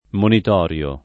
vai all'elenco alfabetico delle voci ingrandisci il carattere 100% rimpicciolisci il carattere stampa invia tramite posta elettronica codividi su Facebook monitorio [ monit 0 r L o ] agg. e s. m.; pl. m. ‑ri (alla lat.